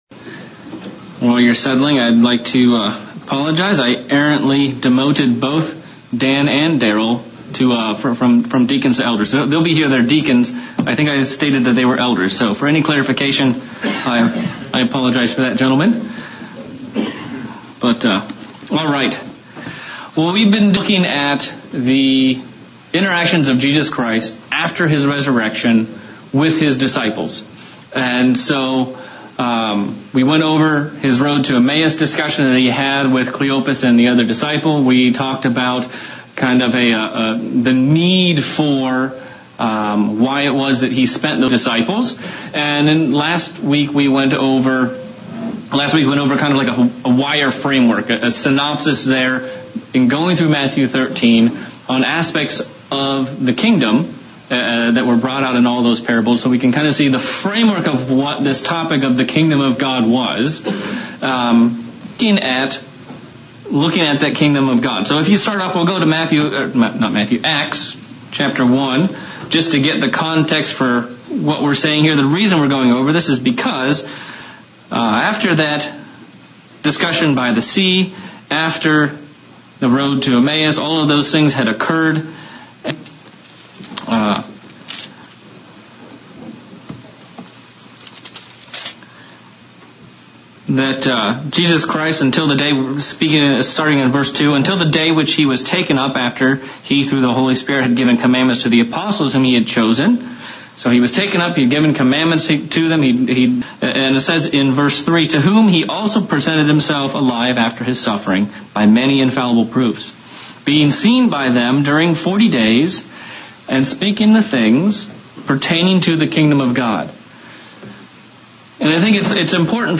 Continuing the series of sermons on the 40 days Christ spent with his disciples after his resurrection and exploring the revolutionary aspect of the Kingdom of God in their lives as well as ours.
Given in Central Illinois